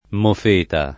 vai all'elenco alfabetico delle voci ingrandisci il carattere 100% rimpicciolisci il carattere stampa invia tramite posta elettronica codividi su Facebook mofeta [ mof % ta ] (meno com. moffetta ) s. f. («fumarola»)